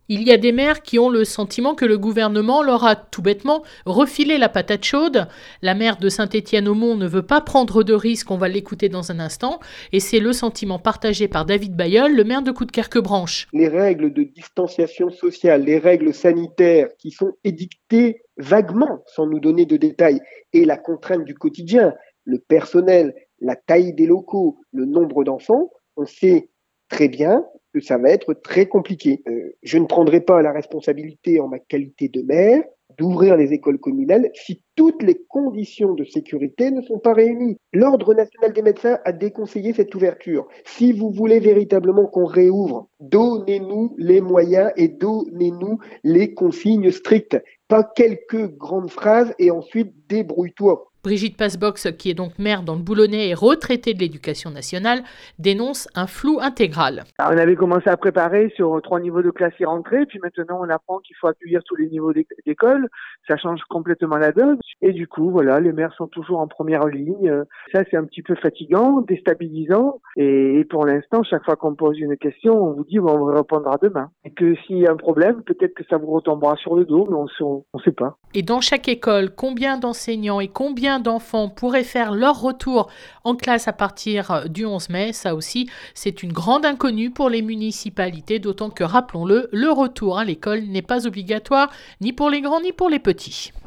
Écoutez l’interview sur la radio préférée des Coudekerquois : Delta Fm et vous connaîtrez mes interrogations dans le cadre de la préparation du déconfinement des écoles.